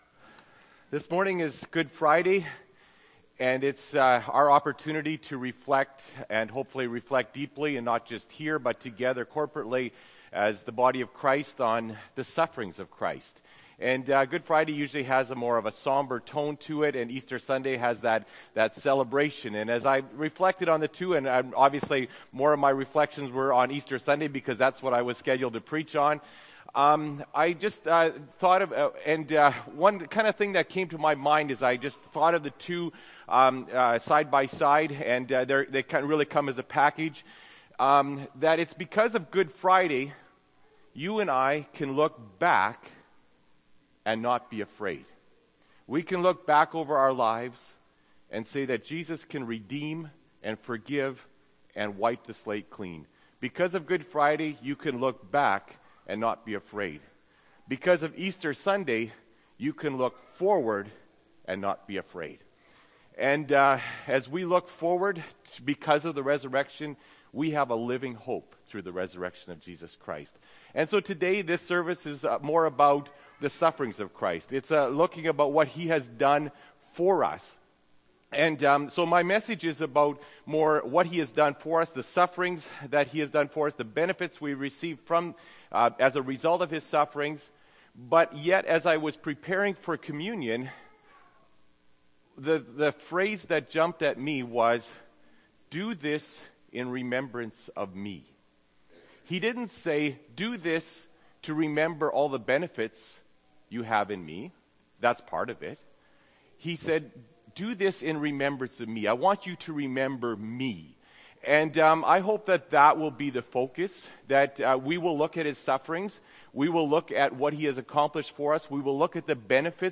Apr. 18, 2014 – Sermon